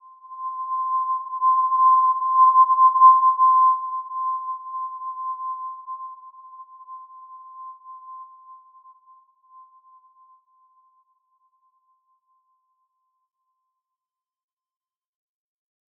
Simple-Glow-C6-p.wav